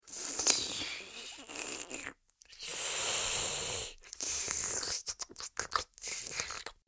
دانلود آهنگ آب 67 از افکت صوتی طبیعت و محیط
جلوه های صوتی
دانلود صدای آب 67 از ساعد نیوز با لینک مستقیم و کیفیت بالا